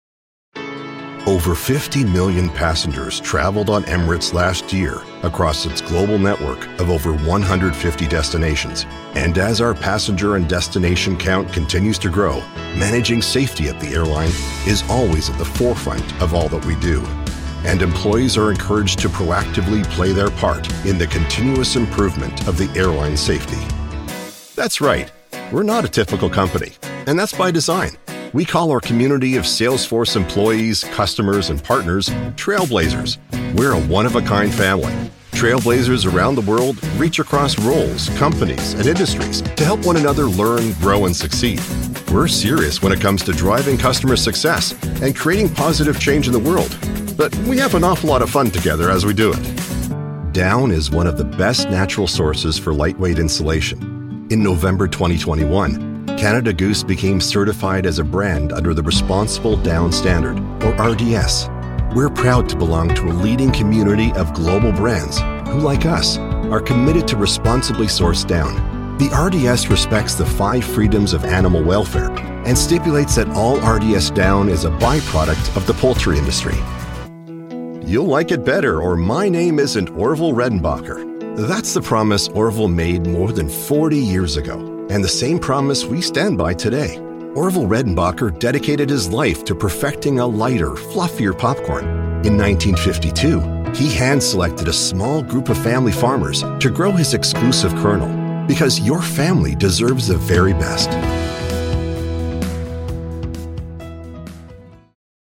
English (Canadian)
Corporate Videos
CAD E100S large diaphragm condenser microphone, Steinberg UR22 interface, Mogami cables, custom-built recording studio, Mac Mini running Adobe Audition CC and full connectivity including SourceConnect Standard for directed sessions.
BaritoneBassDeepLow